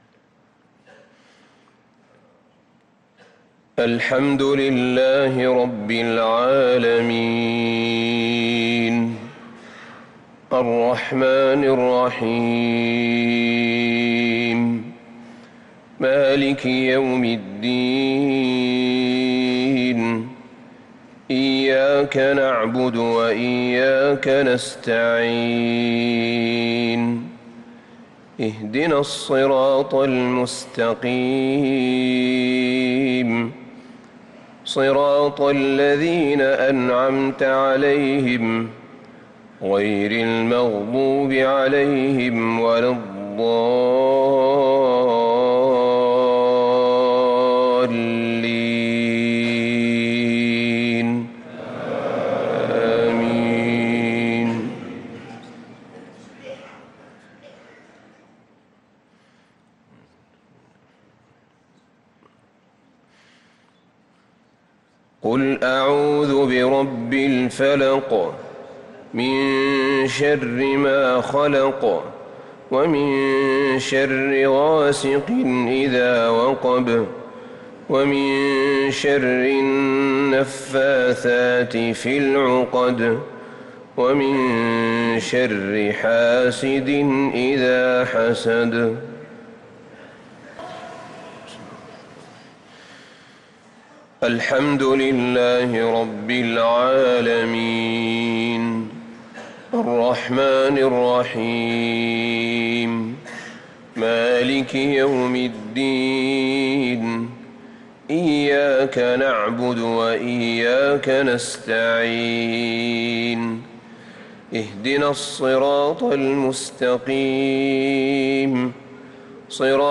صلاة المغرب للقارئ أحمد بن طالب حميد 19 ذو الحجة 1444 هـ
تِلَاوَات الْحَرَمَيْن .